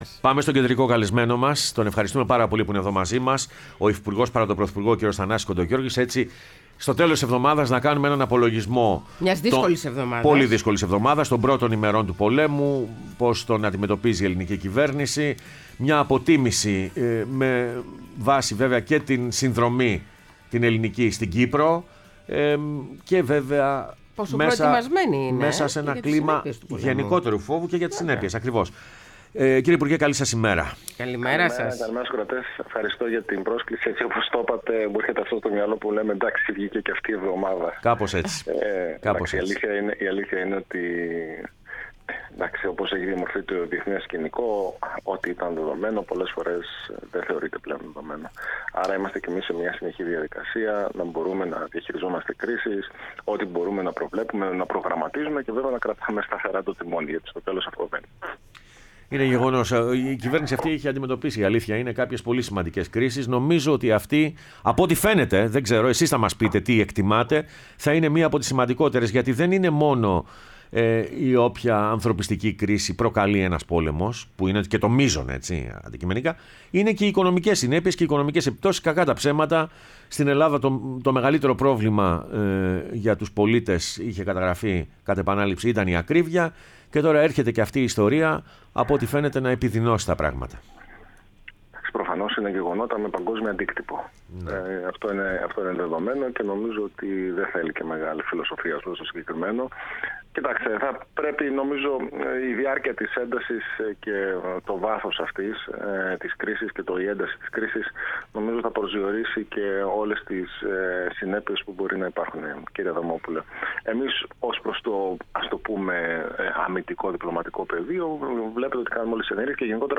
Ο Θανάσης Κοντογεώργης ,Υφυπουργός παρά τω Πρωθυπουργώ μίλησε στην εκπομπή Πρωινές Διαδρομές